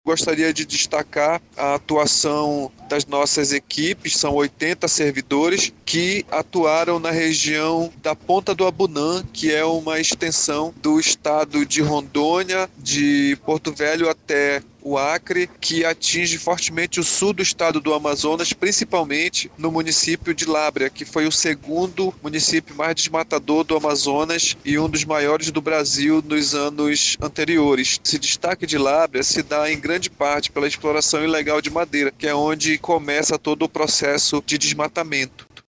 Ainda de acordo com Joel Araújo, as ações iniciais da operação se concentraram em áreas como a do Sul do Amazonas, que se destaca com altos índices de desmatamento.